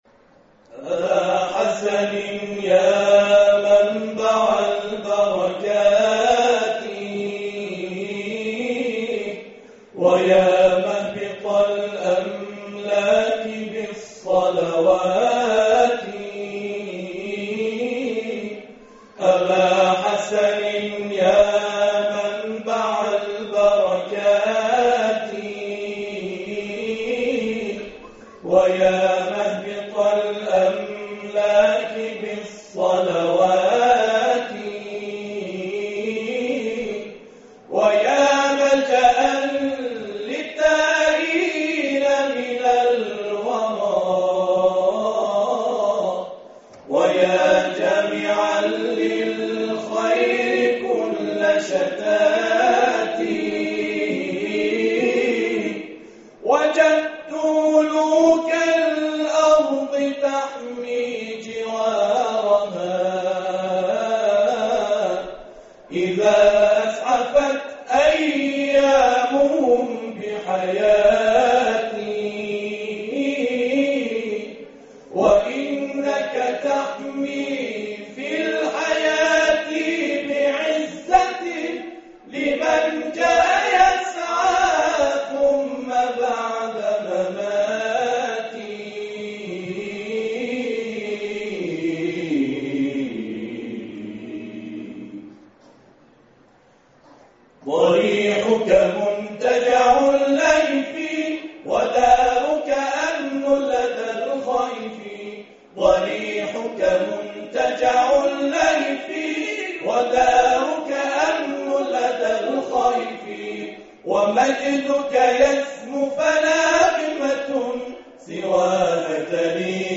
تواشیح
گروه تواشیح سلسله الذهب خراسان